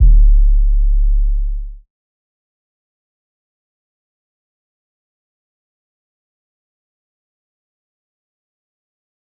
JJCustom808s (7).wav